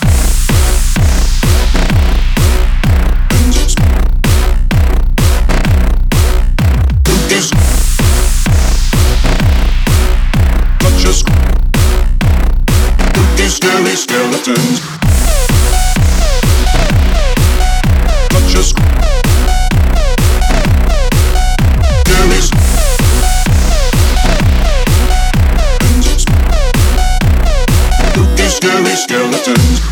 # Children's Music